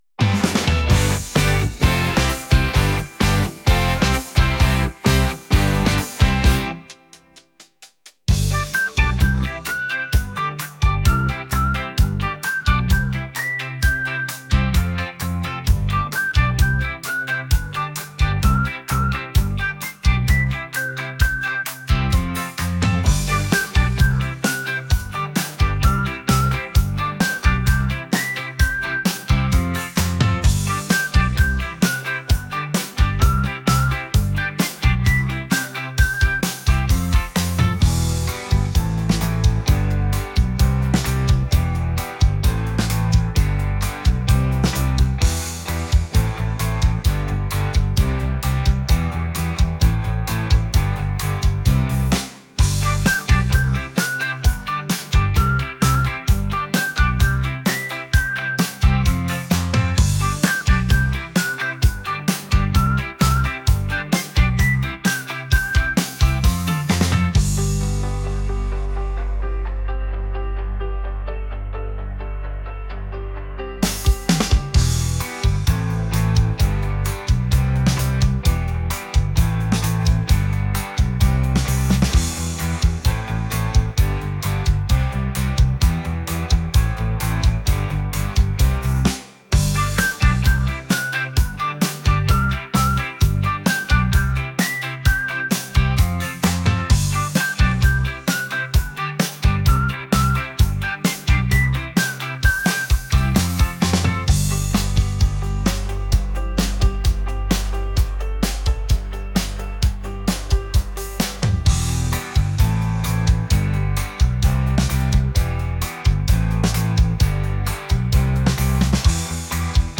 catchy | pop | upbeat